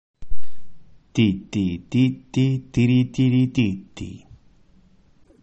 Ritmo 2
ritmo_2n.mp3